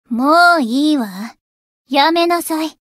灵魂潮汐-敖绫-互动-厌恶的反馈.ogg